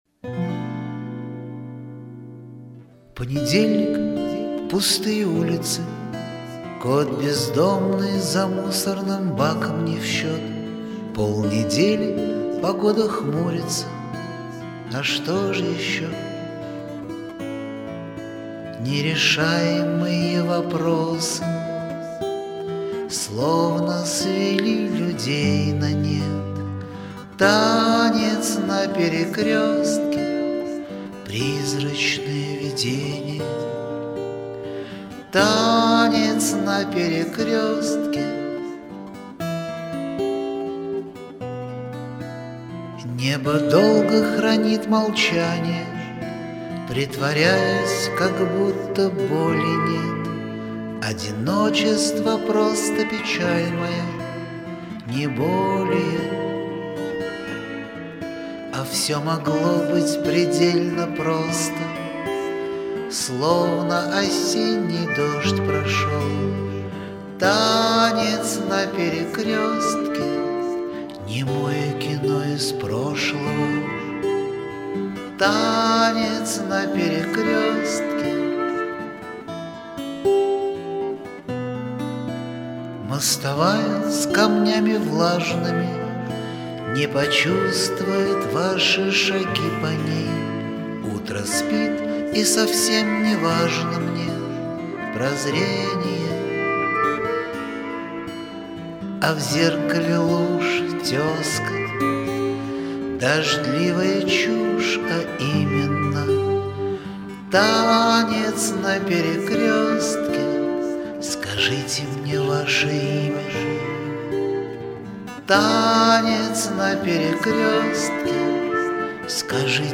• Жанр: Бардрок